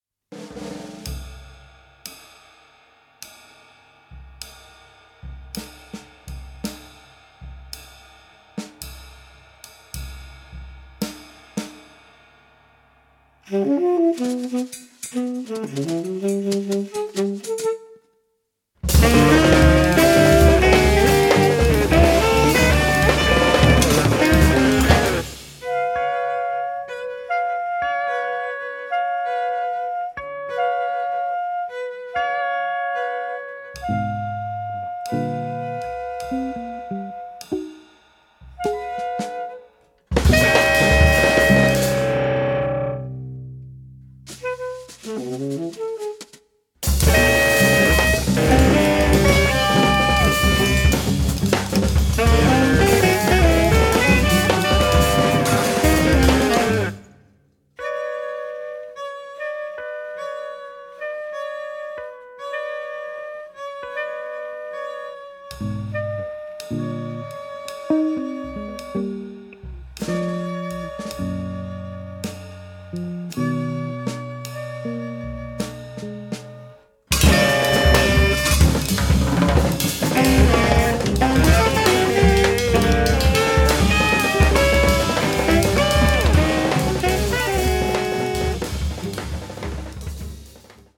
electric guitar
tenor saxophone
cello
percussion
Recorded in Cologne at Topaz Studios
this is instrumental music.